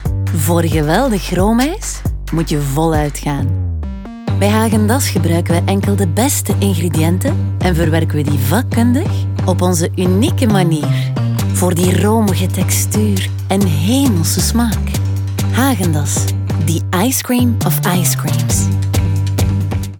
Natural, Cálida, Suave, Accesible, Amable
Comercial